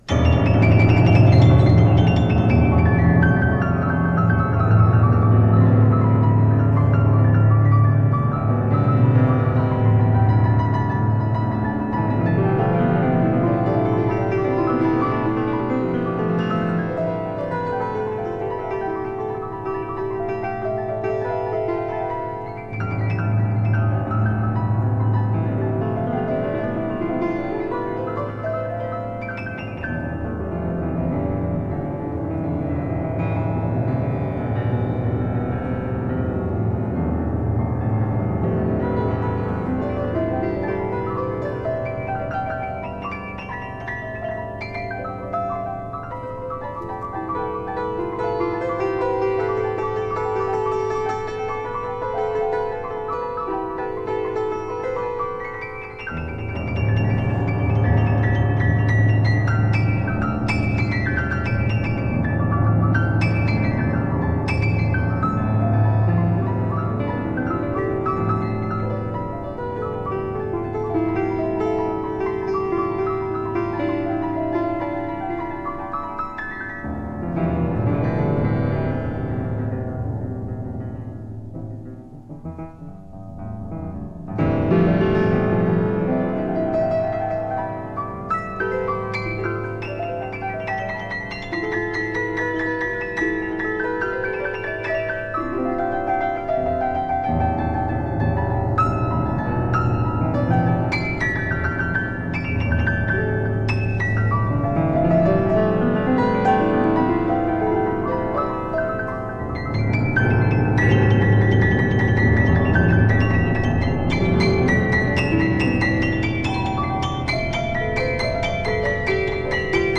sept puissantes improvisations au piano